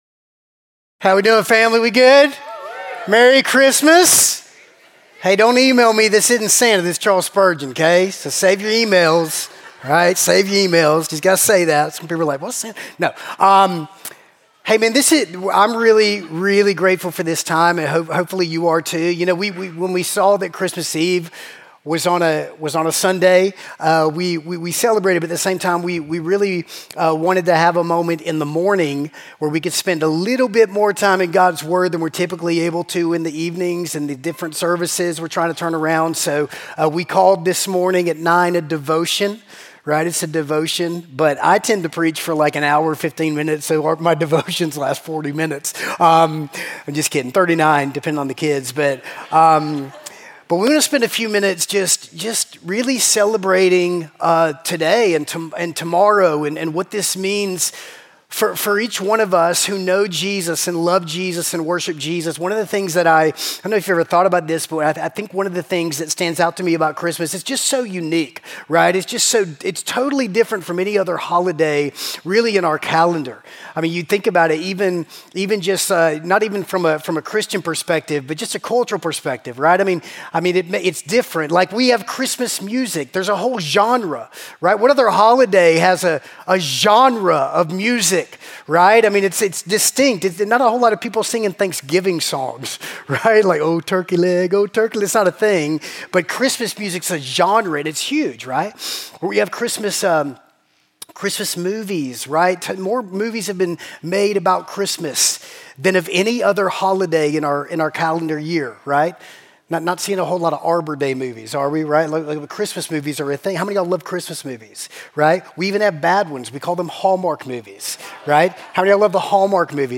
Christmas Eve Morning Service (Matthew 2:1-18)